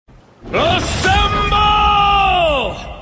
captain america marvel rivals Meme Sound Effect
Category: Games Soundboard